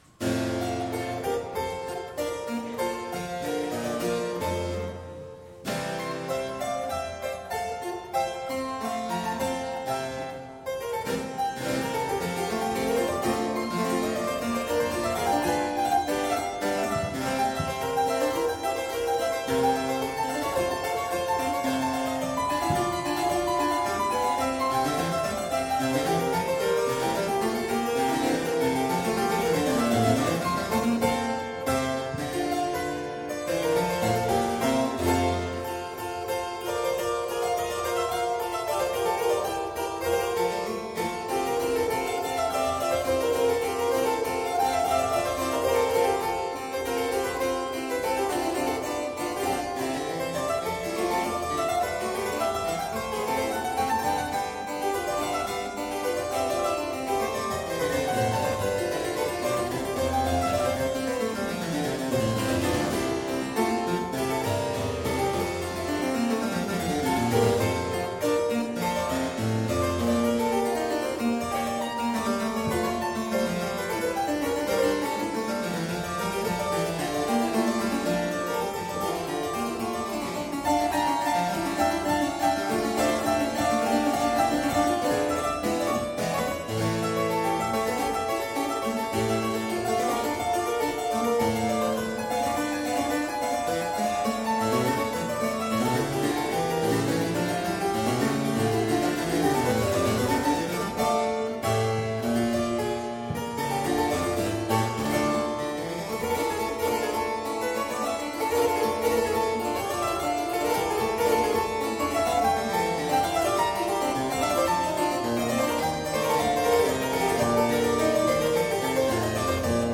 Style: Classical
Audio: Boston - Isabella Stewart Gardner Museum
harpsichord